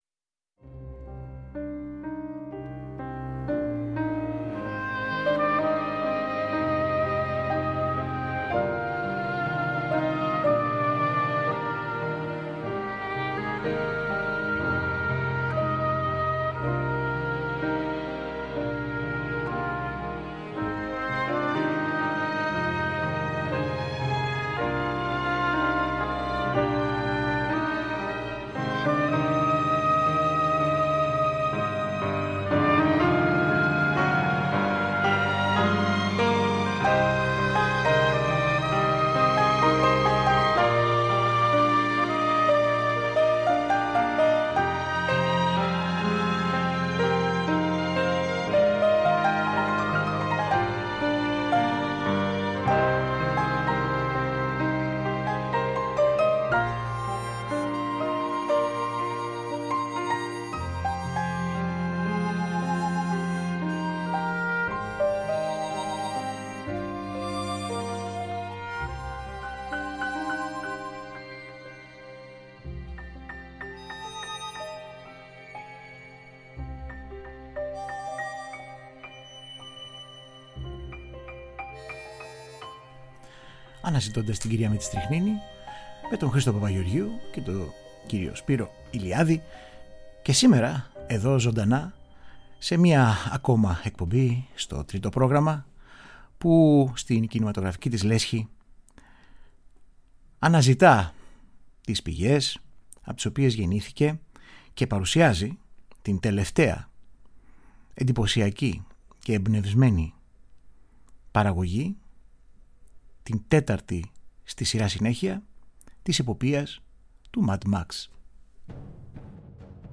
Η αντισυμβατική μουσική
Original Soudtracks
κινηματογραφικη μουσικη